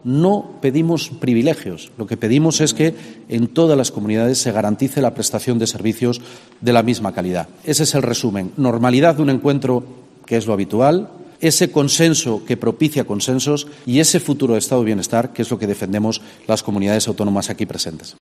Barbón se ha pronunciado en estos términos en su comparecencia pública tras su participación este martes junto con los presidentes de otras siete comunidades autónomas, en el 'Foro de Santiago.